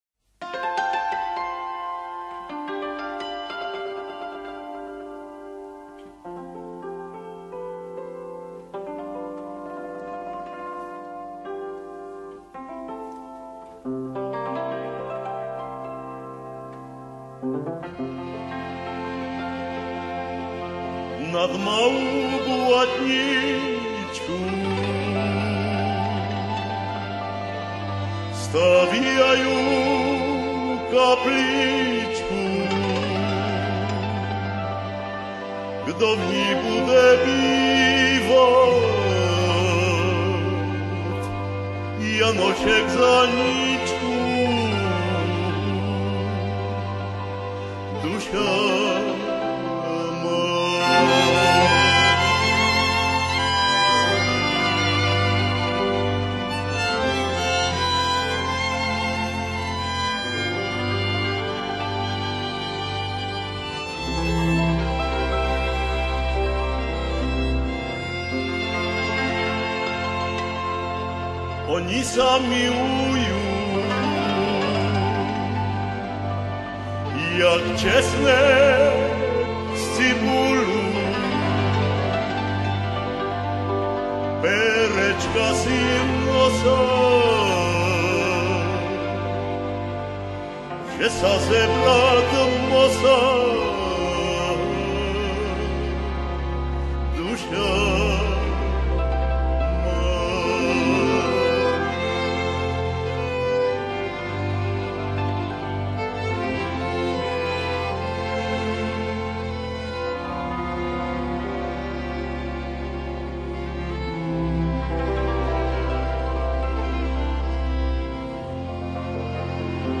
Připomeneme si ho písničkami, které nasbíral, upravil, zazpíval a zahrál